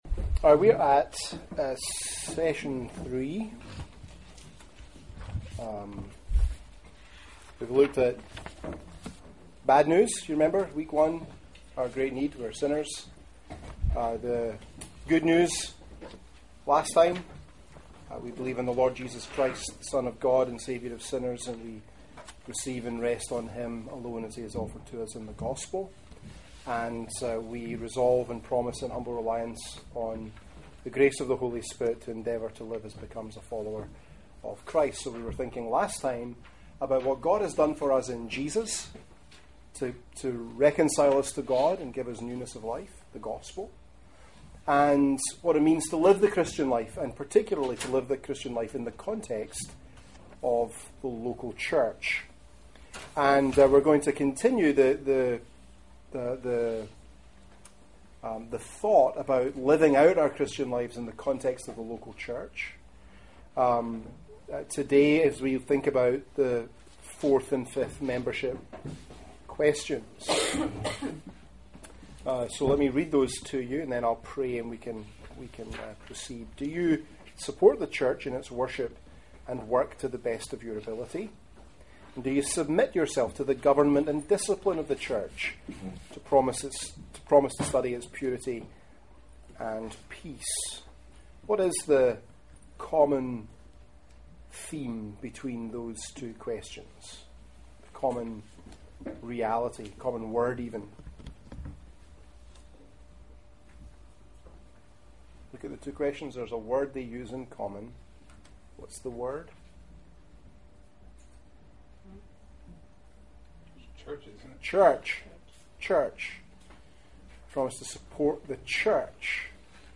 Foundations Class